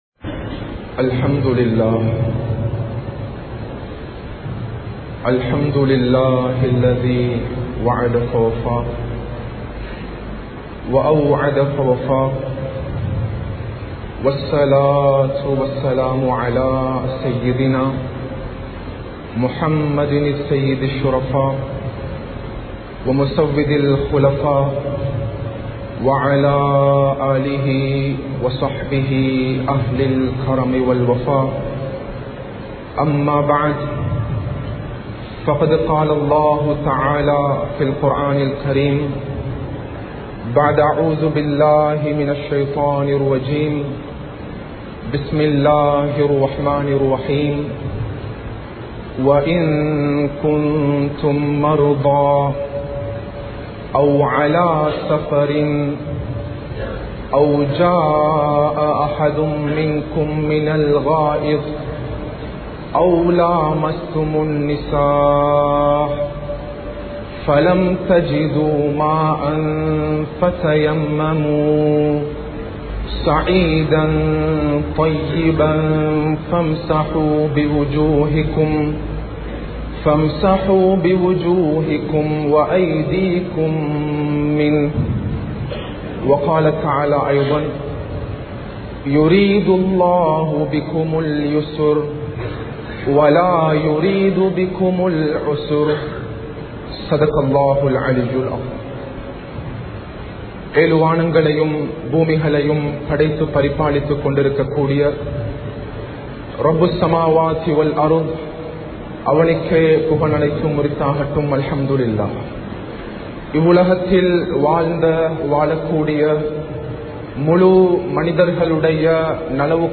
தயம்மும் செய்யும் முறை | Audio Bayans | All Ceylon Muslim Youth Community | Addalaichenai
Anuradapura, Nachiyaduwa Jummah Masjith